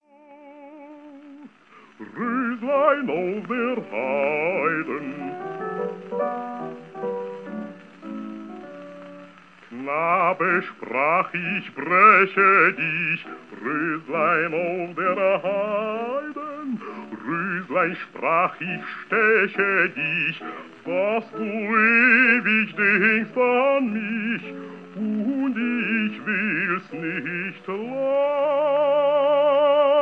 bass
piano